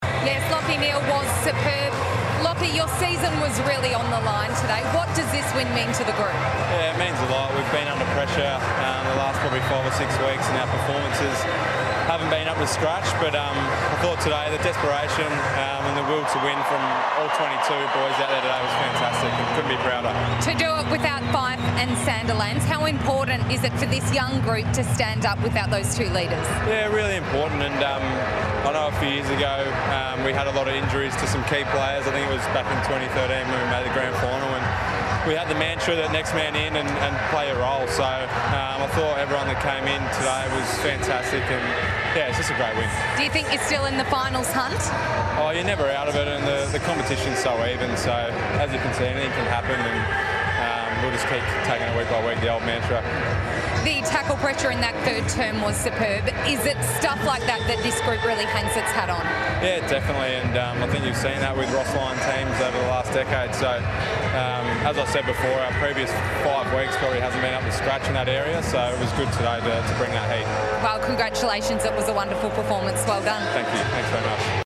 Lachie Neale spoke to FOX Footy post-match following the win over Adelaide.